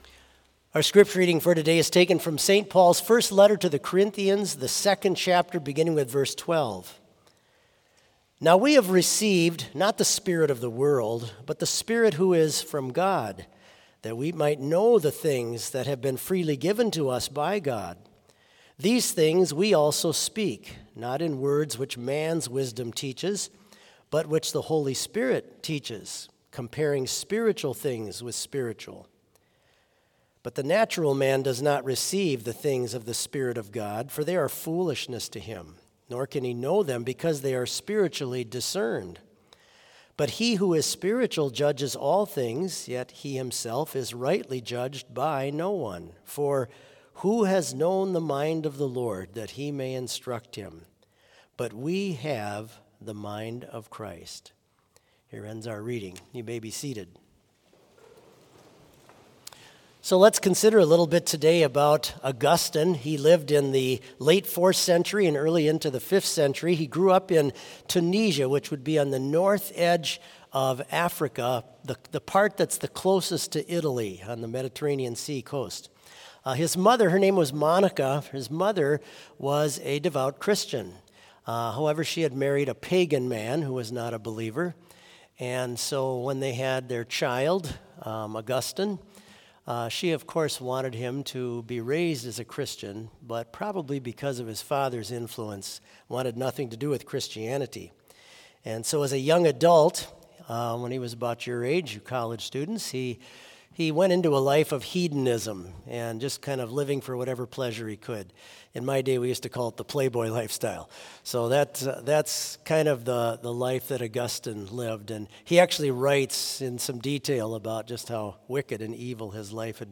Complete service audio for Monday Chapel - August 28, 2023